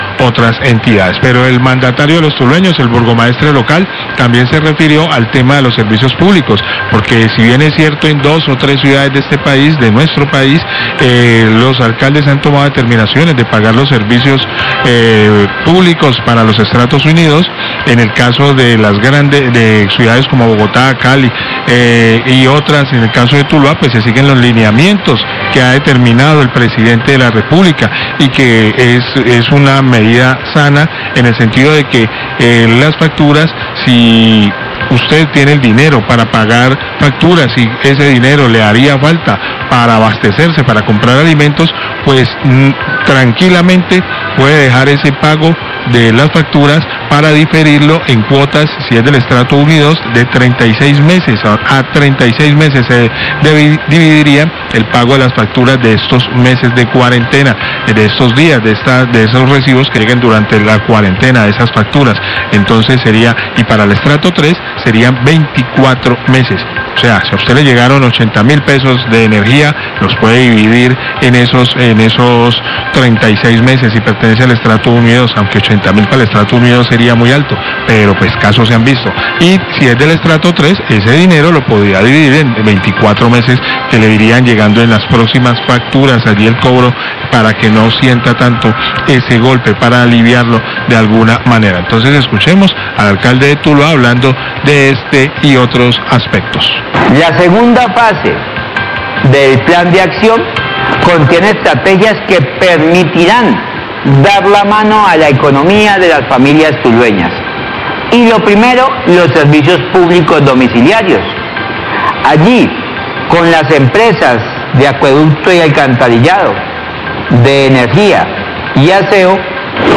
Radio
Alcalde de Tuluá John Jairo Gómez Aguirre explicó a los tulueños que en el municipio se sigue el lineamiento de la presidencia de la república y los tulueños puede dar prioridad a la compra de alimentos y pagar los servicios públicos financiados hasta en 36 meses si se pertenece al estrato 1 y 2, es el estrato tres lo puede financiar hasta en 24 meses.